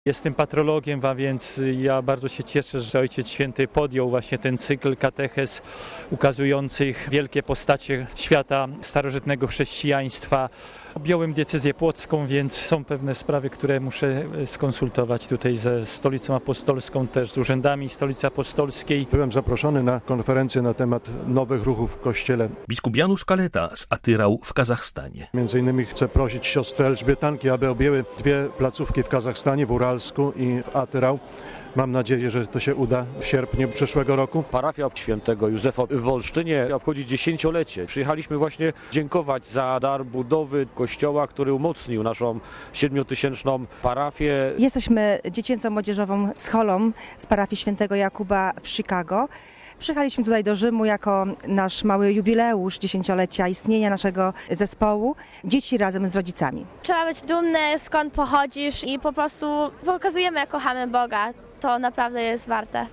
W audiencji na Placu św. Piotra uczestniczyło ponad 20 tys. wiernych.
Z wypowiedzi pielgrzymów: RealAudio